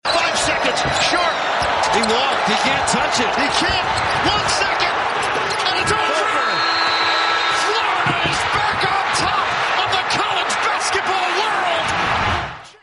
That was Ian Eagle with the call of the game on CBS.